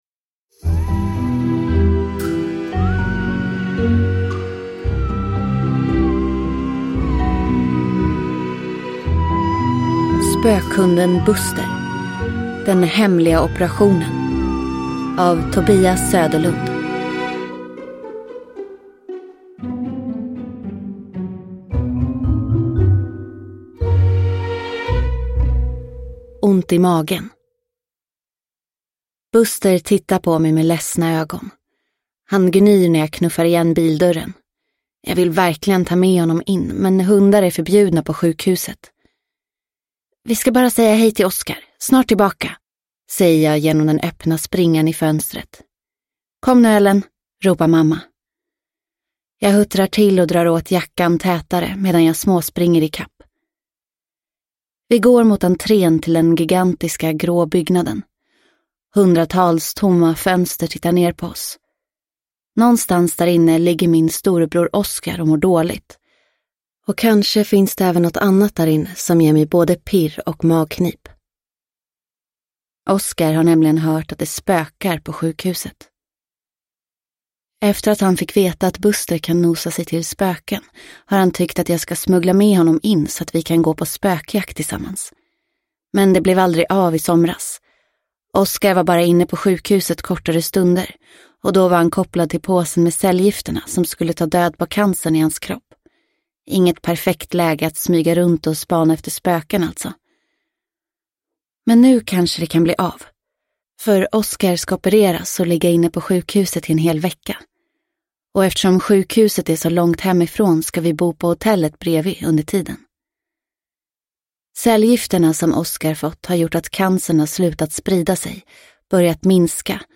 Den hemliga operationen – Ljudbok – Laddas ner